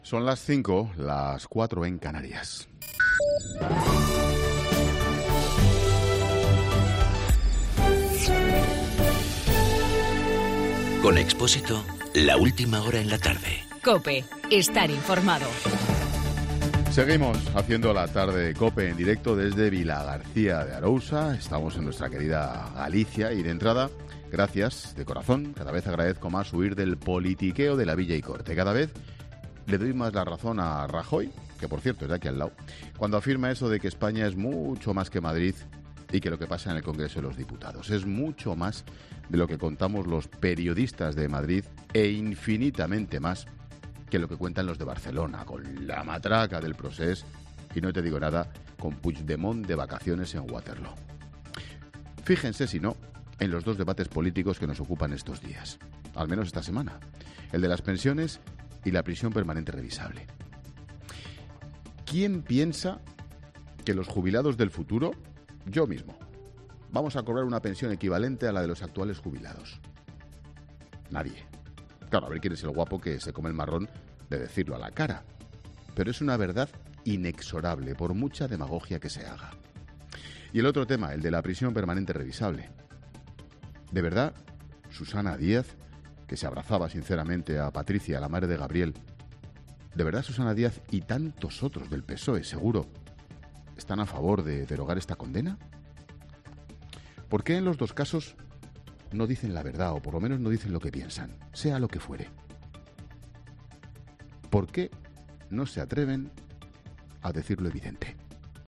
Monólogo de Expósito
Comentario de Ángel Expósito sobre el futuro del sistema de pensiones.